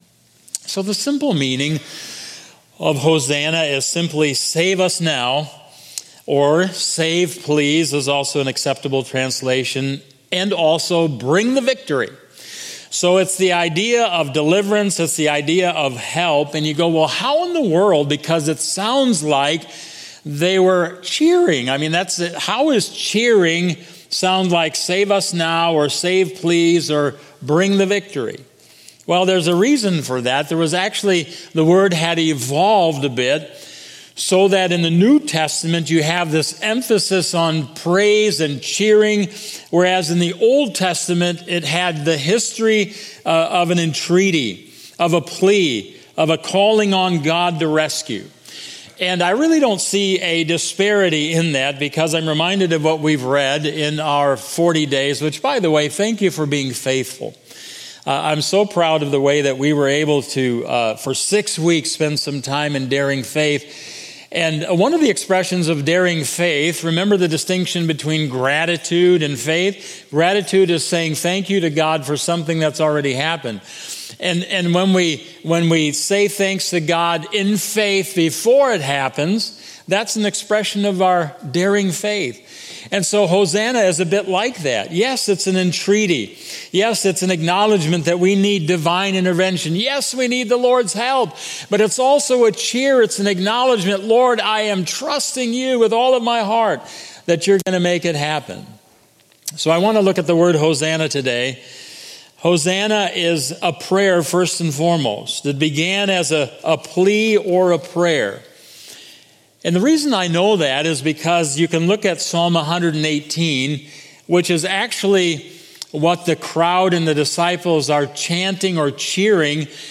Sermon-4-13-25.mp3